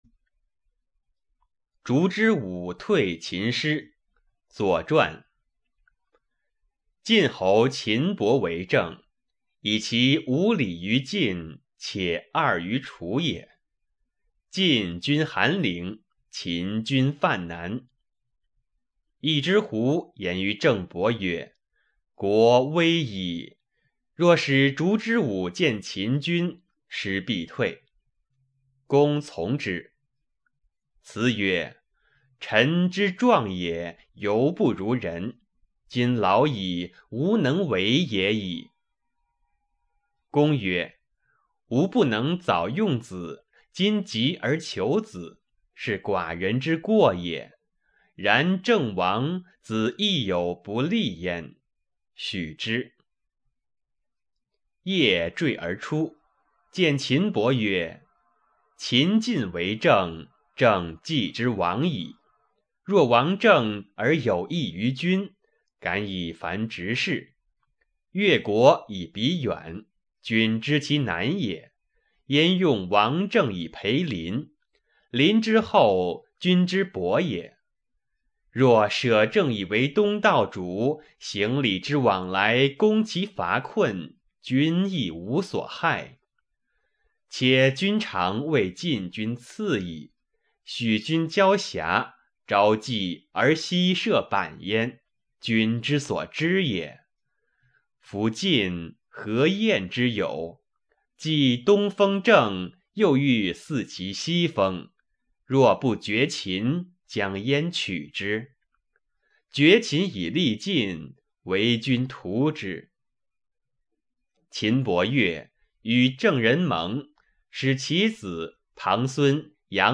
《烛之武退秦师》原文和译文（含朗读）